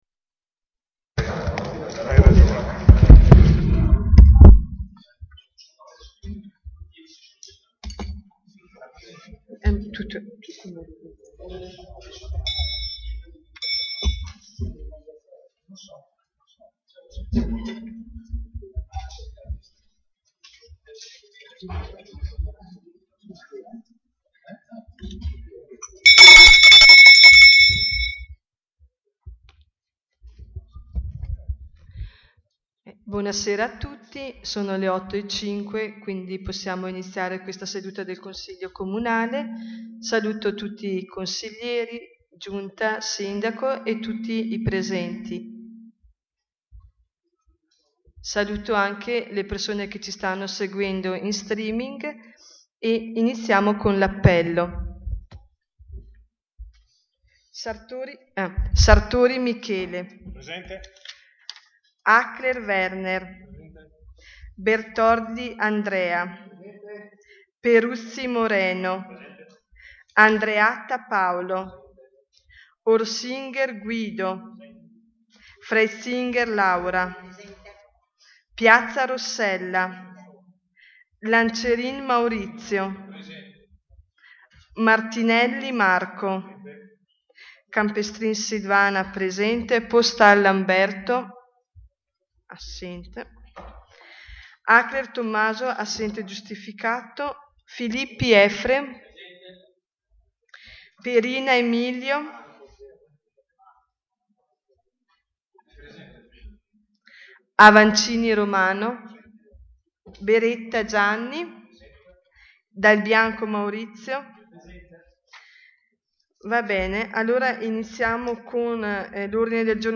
Seduta - data Martedì, 26 Gennaio 2016 / Sedute del Consiglio / Attività del consiglio / Il Consiglio Comunale / Organi politici / In Comune / Comune di Levico Terme - Comune di Levico Terme